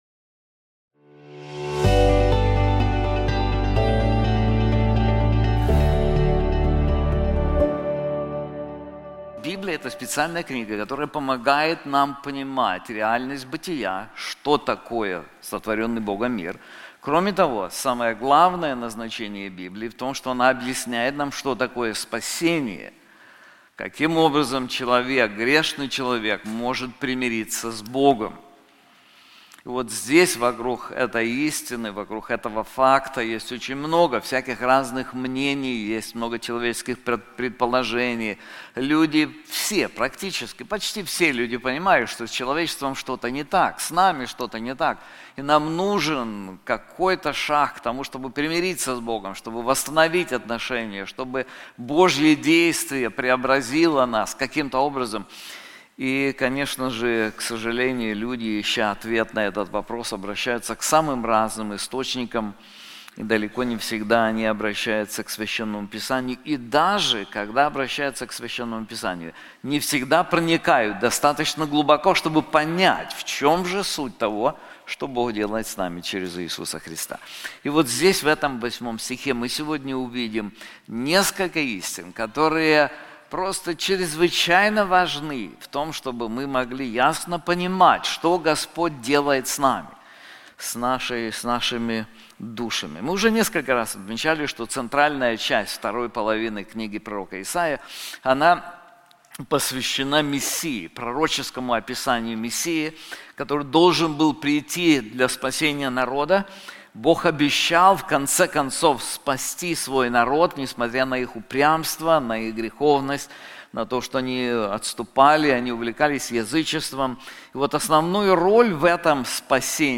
This sermon is also available in English:The Covenant in Jesus Christ • Isaiah 49:8